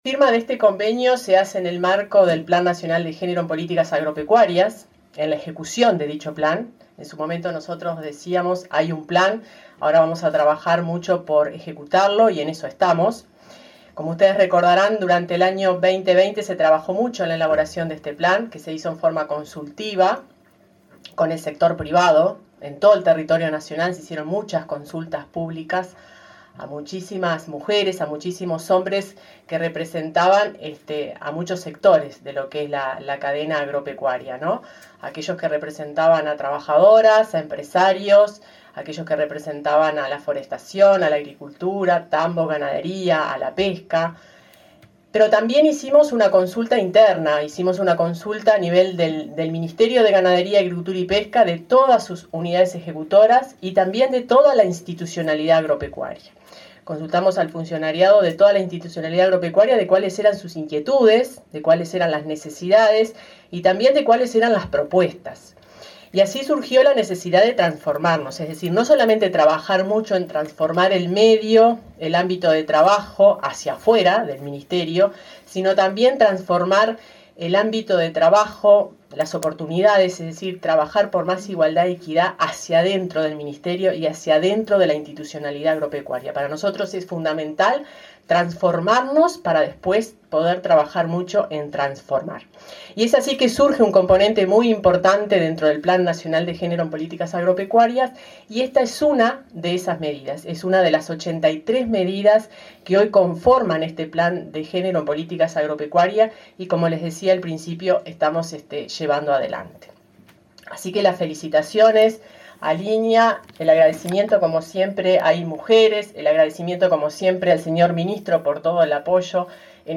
Palabra de autoridades en convenio entre INIA e Inmujeres
Palabra de autoridades en convenio entre INIA e Inmujeres 04/07/2022 Compartir Facebook X Copiar enlace WhatsApp LinkedIn El Instituto Nacional de las Mujeres (Inmujeres) y el Instituto Nacional de Investigación Agropecuaria (INIA) firmaron un convenio para implementar un modelo de calidad con equidad de género. La directora general del Ministerio de Ganadería, Fernanda Maldonado; el responsable del INIA, José Bonica; la directora del Inmujeres, Mónica Bottero, y el ministro Fernando Mattos resaltaron la importancia del acuerdo.